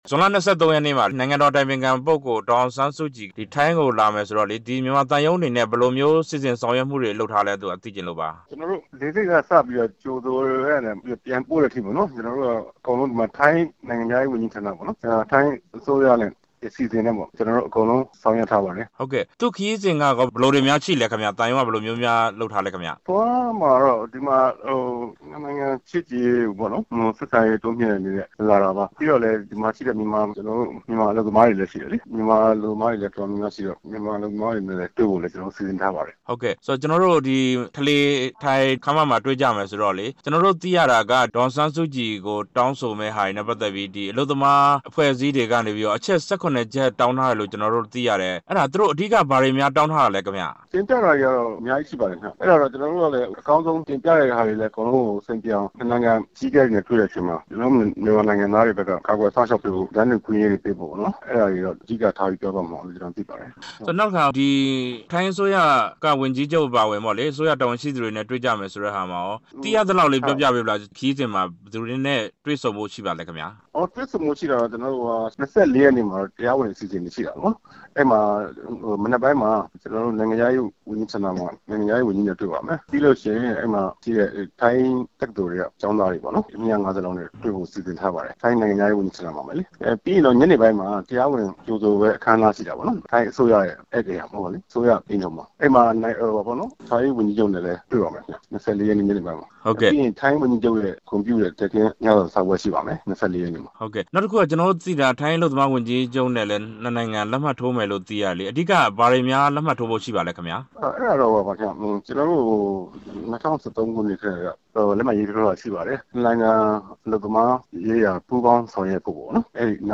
ဒေါ်အောင်ဆန်းစုကြည်ရဲ့ ထိုင်းခရီးစဉ် အခြေအနေ မေးမြန်းချက်